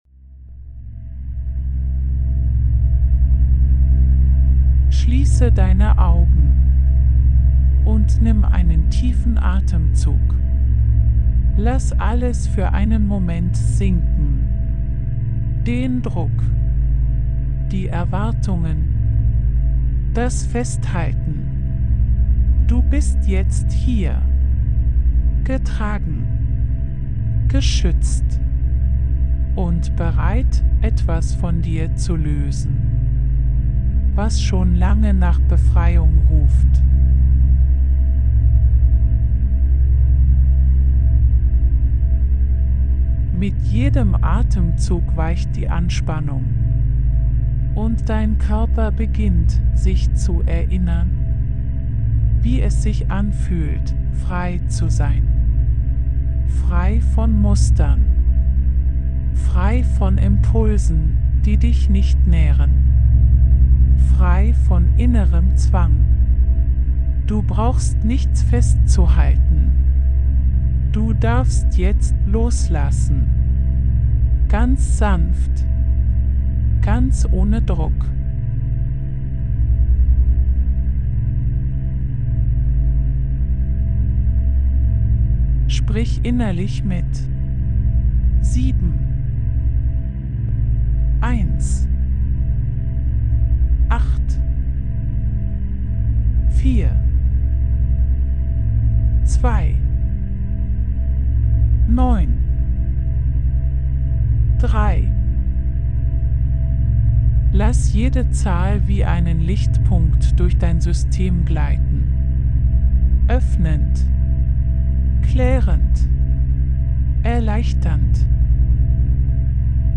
🎧 Die Audio-Meditation zum